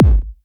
Index of /90_sSampleCDs/Club_Techno/Percussion/Kick
Kick_25.wav